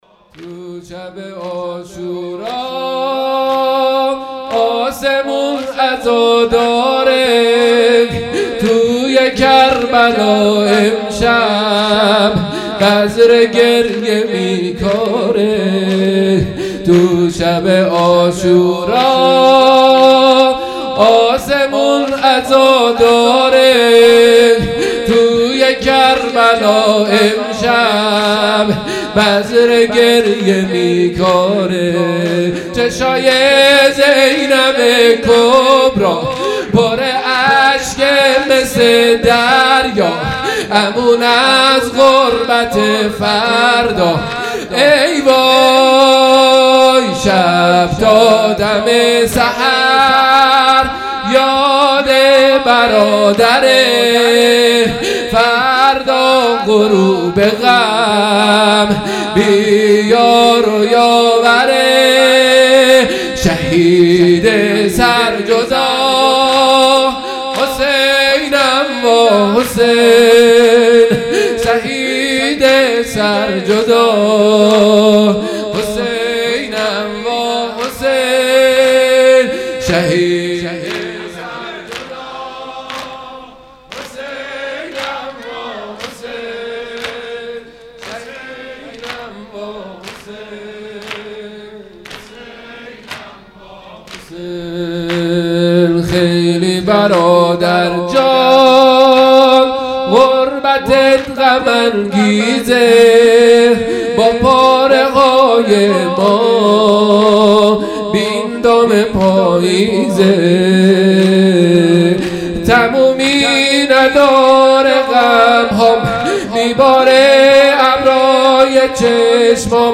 زمینه| تو شب عاشورا آسمون عزاداره
محرم1442_شب دهم